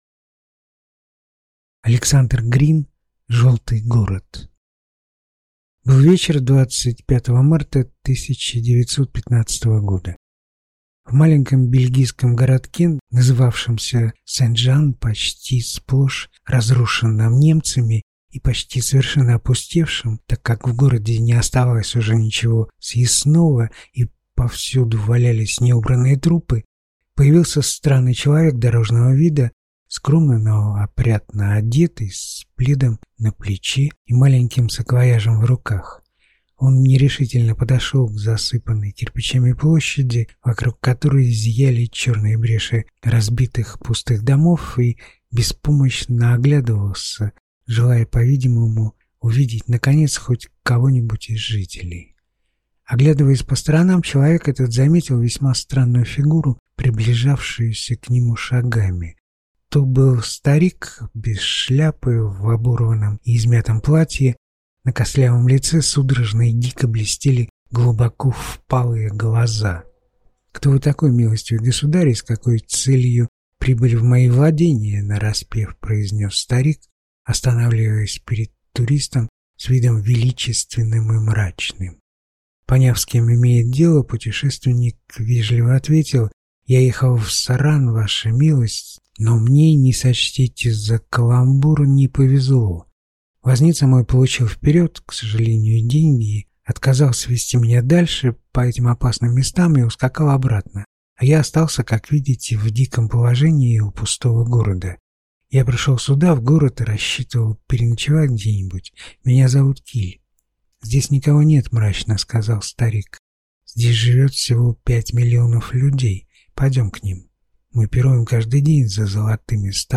На данной странице вы можете слушать онлайн бесплатно и скачать аудиокнигу "Желтый город" писателя Александр Грин.